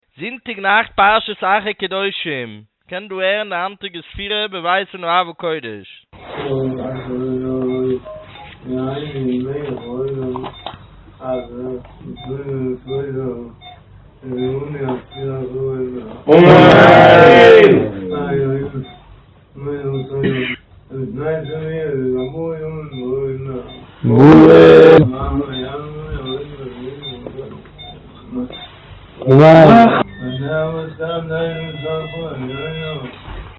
האדמו"ר ערך במעונו את ספירת העומר, כמנהגו בקודש למרות החולשה הרבה שחש.